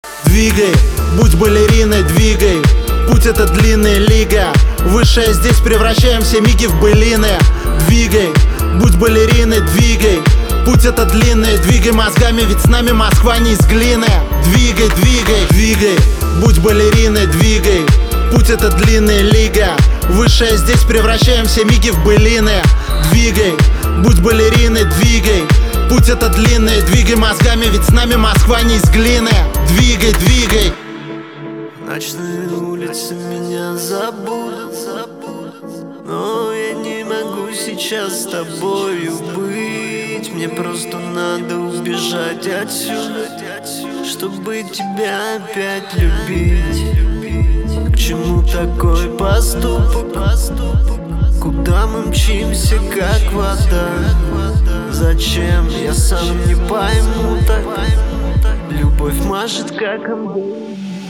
• Качество: 320, Stereo
мужской вокал
рэп
Хип-хоп